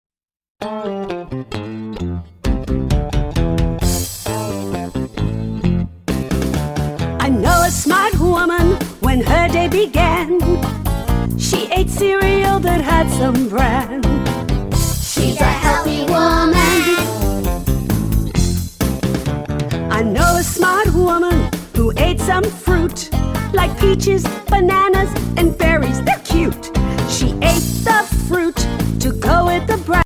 Learn science with these fun and upbeat songs!